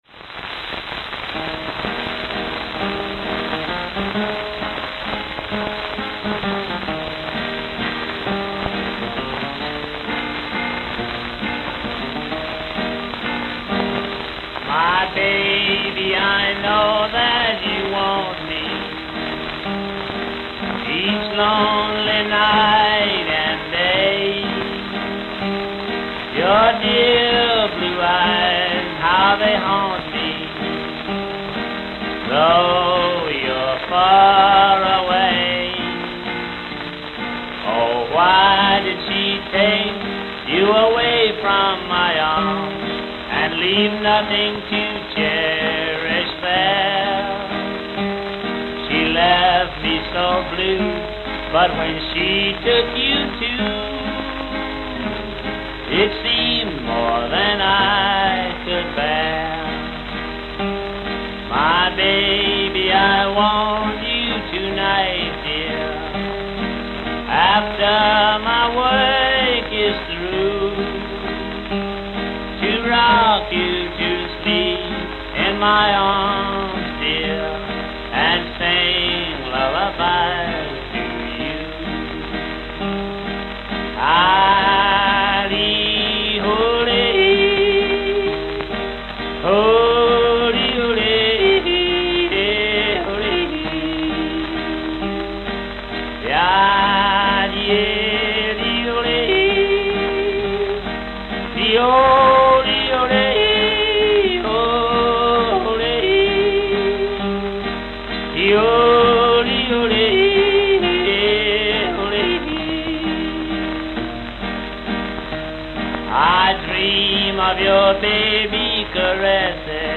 Camden, New Jersey Camden, New Jersey
Note: Worn. Note: Worn.